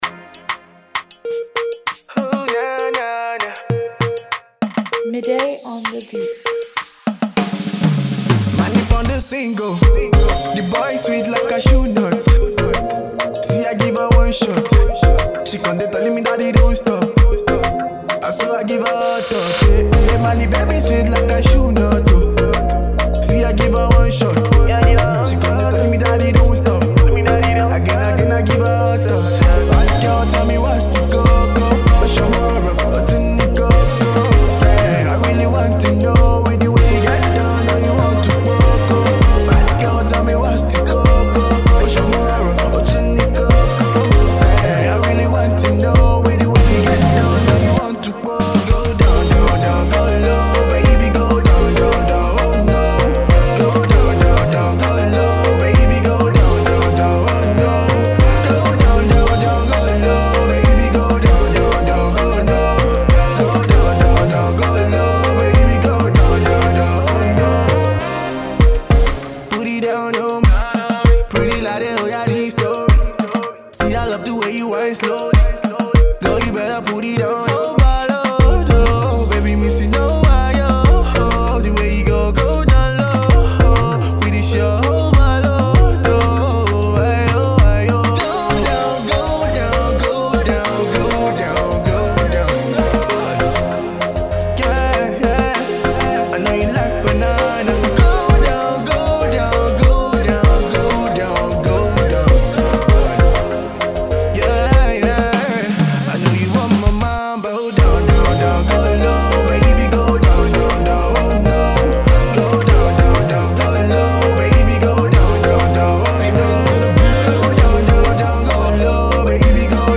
dance tune